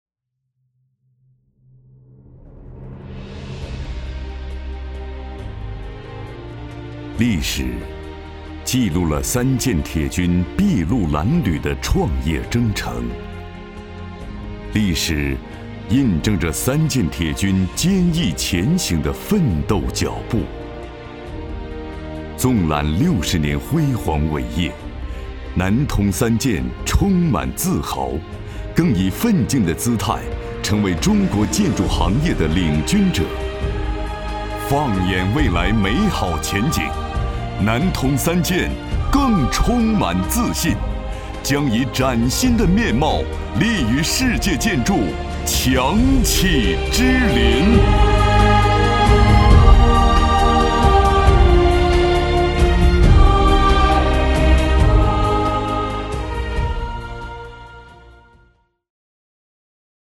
配音风格： 沉稳 稳重